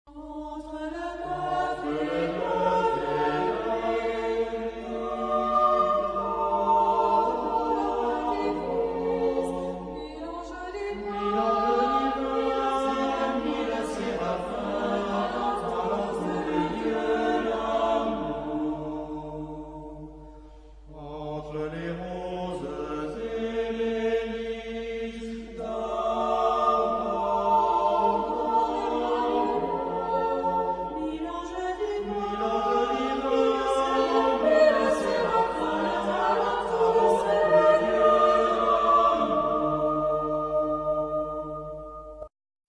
Genre-Style-Forme : noël ; Populaire
Type de choeur : SAH  (3 voix mixtes )
Tonalité : ré mineur